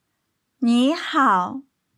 音声を聞いていただくとわかりますが、私たち日本人がカタカナの「ニーハオ」を読んだときと、実際の中国語の発音は音の上がり下がりが違いますよね？ 中国語はこの音の上がり下がり（声調）がとても大事で、これが正しくないと違う言葉になってしまい、通じません。